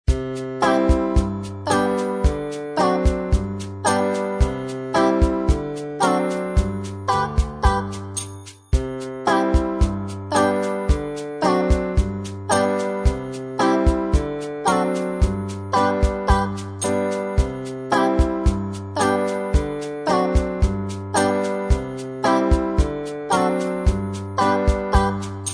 Instrumental mp3 Track with Background Vocals